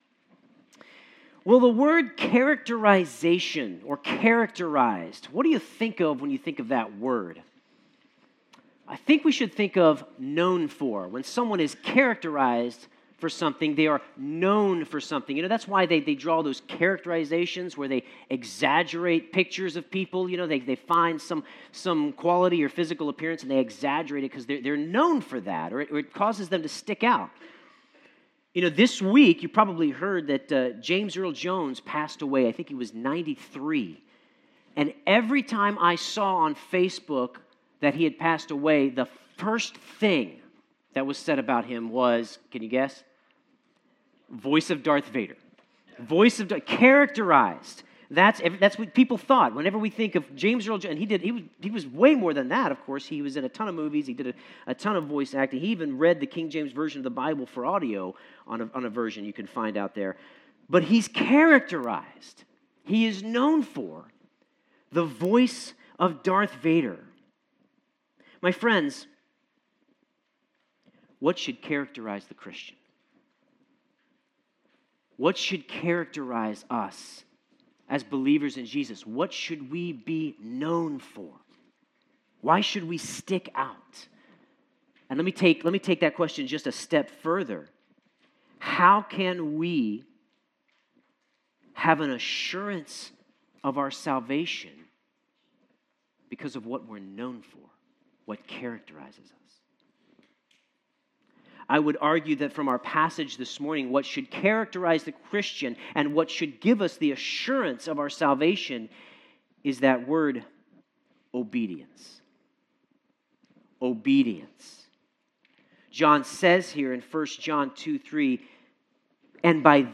Sermon Notes Followers of Christ should be characterized by obedience to Him.